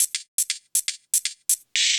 Index of /musicradar/ultimate-hihat-samples/120bpm
UHH_ElectroHatA_120-02.wav